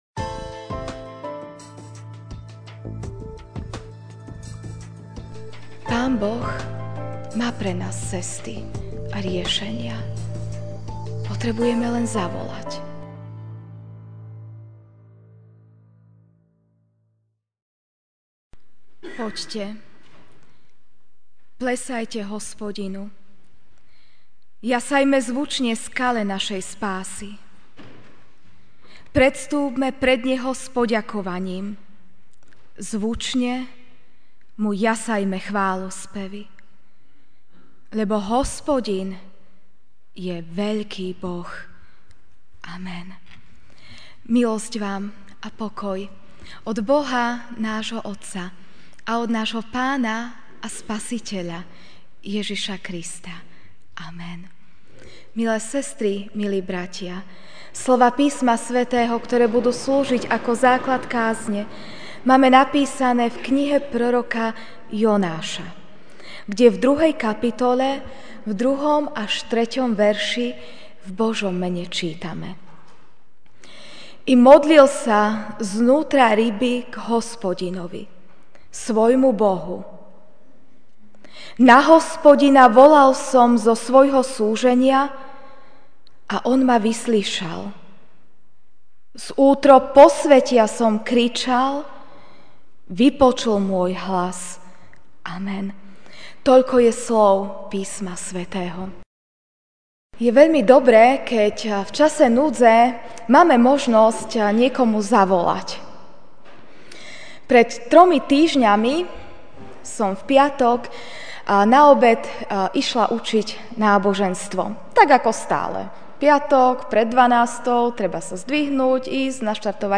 Ranná kázeň: Rogate - modlite sa (Jon 2, 2-3) I modlil sa zvnútra ryby k Hospodinovi, svojmu Bohu: Na Hospodina volal som zo svojho súženia a On ma vyslyšal.